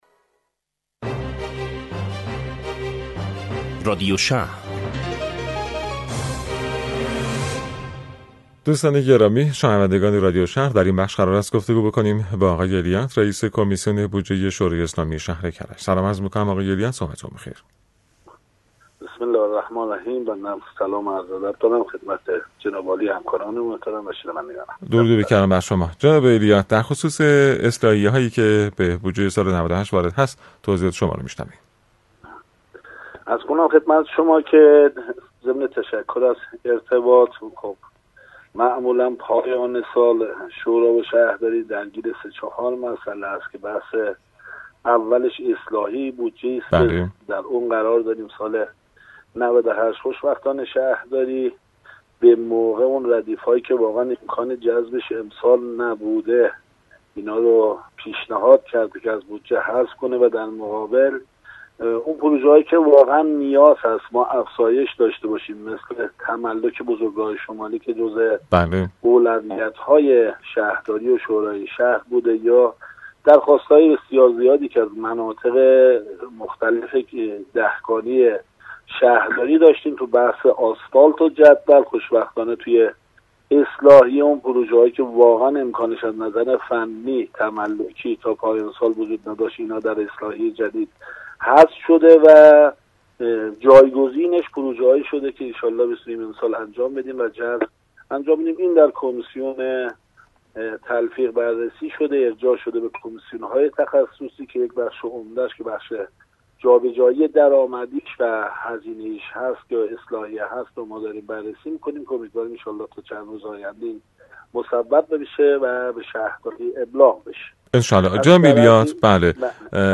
گفت وگوی رادیویی با عضو شورای شهر کرج
فرج الله ایلیات، عضو شورای شهر کرج با رادیو اینترنتی مدیریت شهری شهرداری کرج گفت وگوکرد.